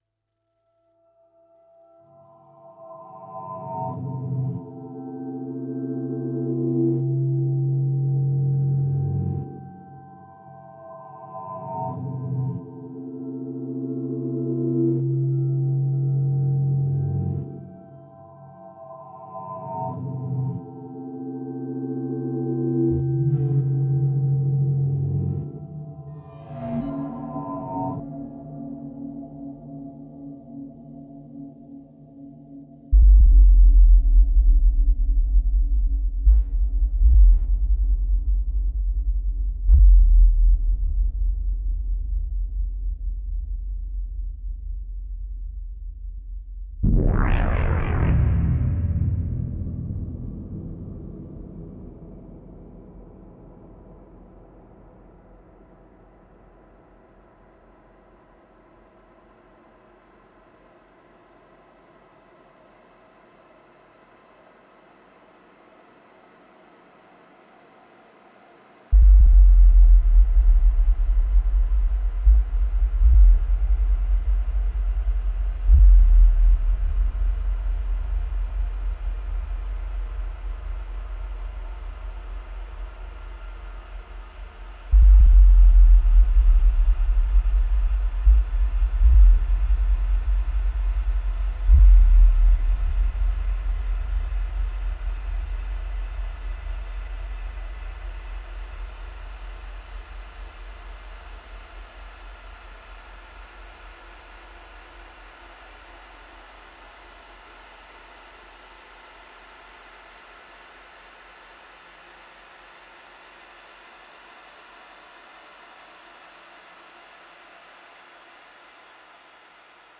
Ableton, Pads created with Behringer Deepmind 6